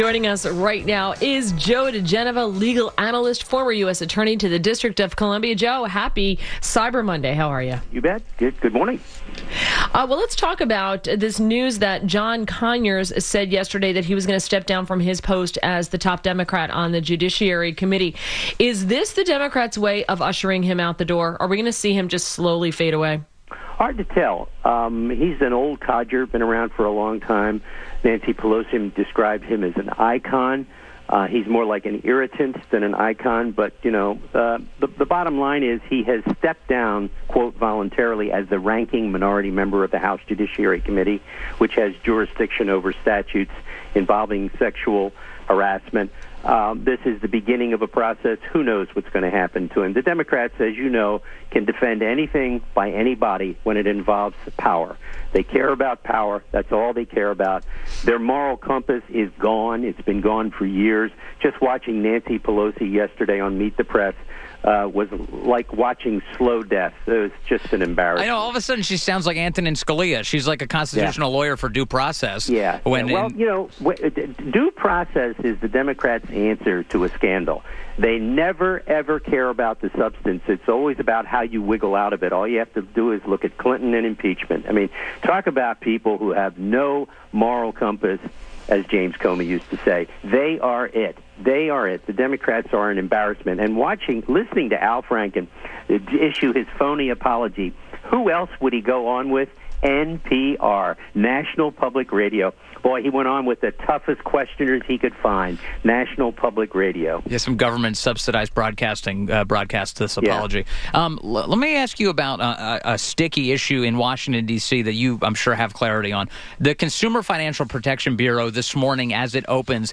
WMAL Interview - JOE DIGENOVA - 11.27.17